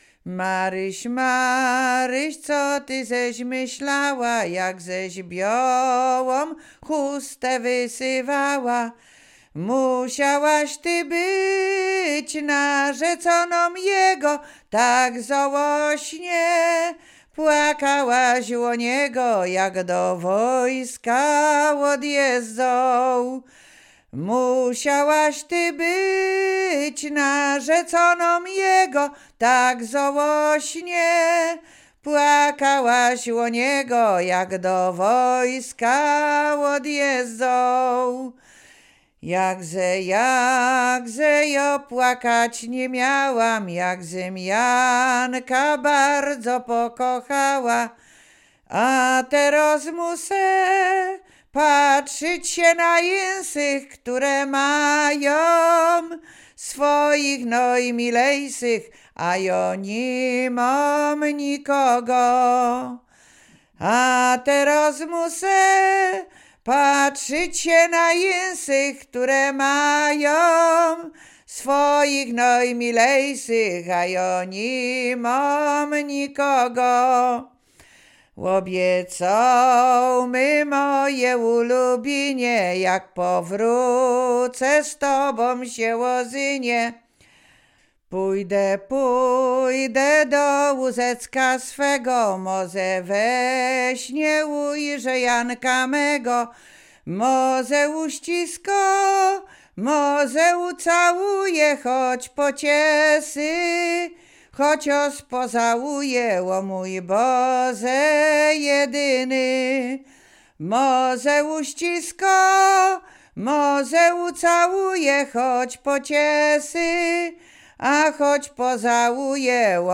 Łowickie
województwo łódzkie, powiat skierniewicki, gmina Lipce Reymontowskie, wieś Drzewce
miłosne liryczne